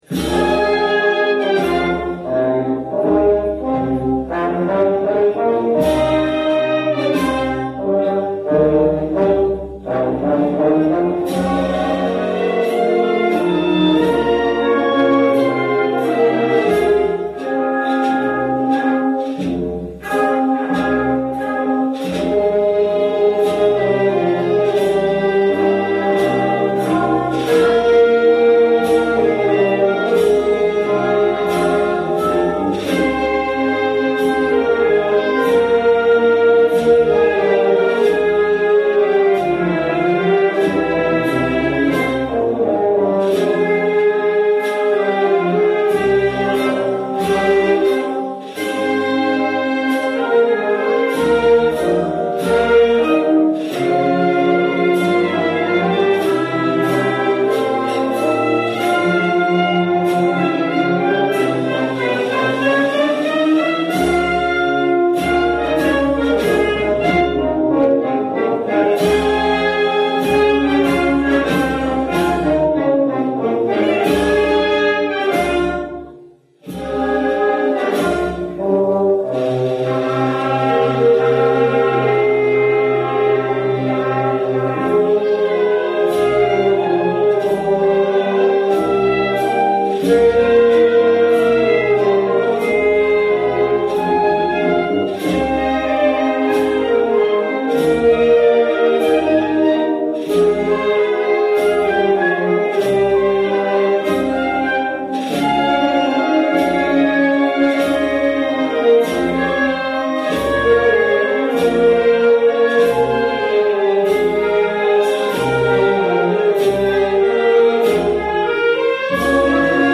La marcha comienza de forma solemne y en alto con dos "llamadas" separadas por breves compases para pasar a un primer tema lleno de tonalidades graves hasta llegar a un fuerte que da paso a un segundo tema muy melódico que culmina con la parte con mas fuerza de la marcha donde casi toda la banda interviene para volver a repetir el primer tema y finalizar.
La grabación fue efectuada por Canal Sur Radio en el año 2001 durante uno de los conciertos de la muestra Munarco realizada en Sevilla. La interpretación corre a cargo de la Banda de Música "Virgen del Castillo" de Lebrija (Sevilla) y tiene una duración de 5'36''.